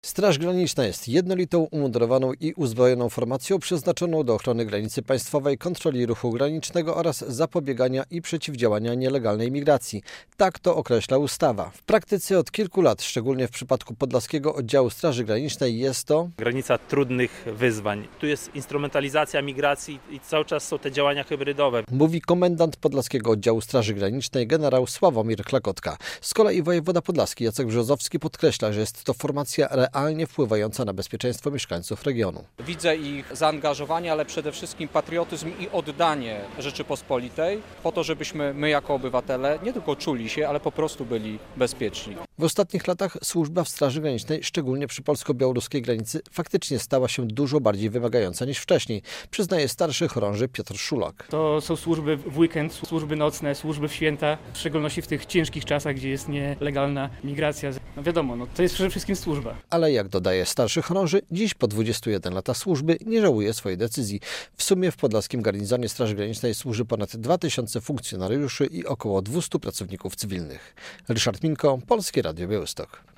Był uroczysty apel w centrum Białegostoku, odznaczenia i awanse na wyższe stopnie.
Święto Straży Granicznej - relacja